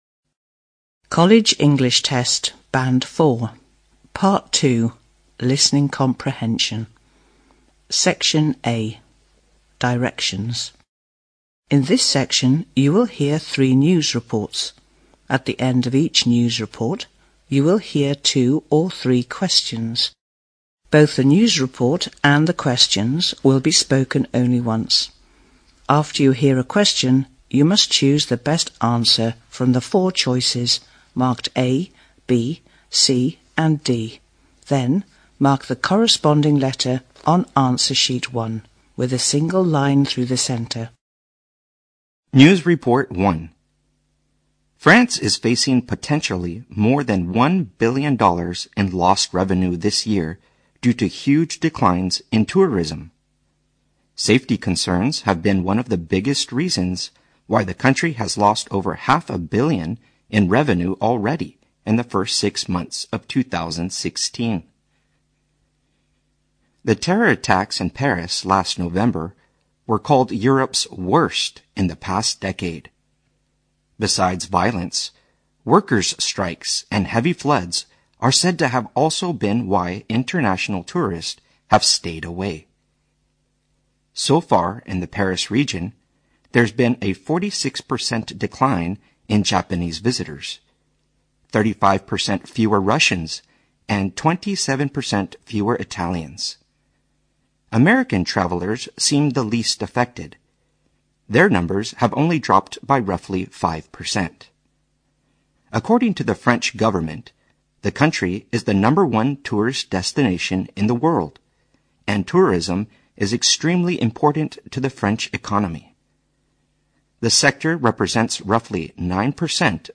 Section A News Report Directions